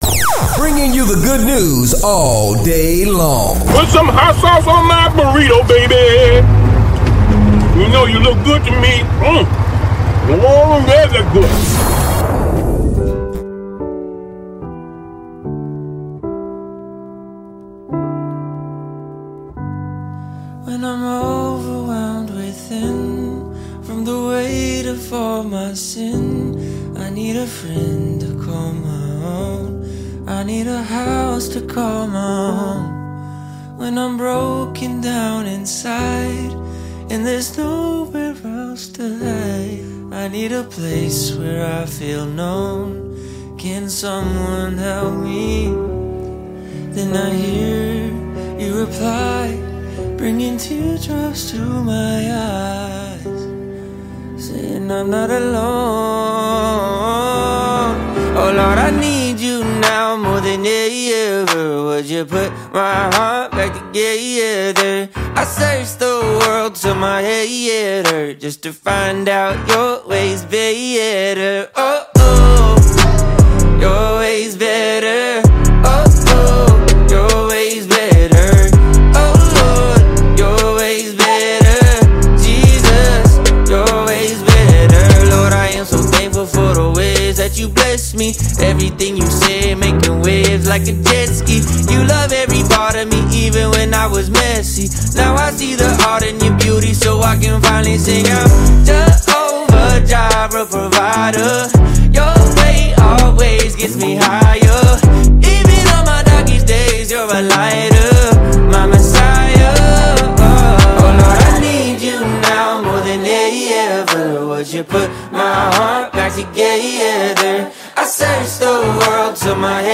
Each week, we blend a diverse mix of Urban and Christian tunes with thought-provoking discussions on the issues that matter most to our neighborhood.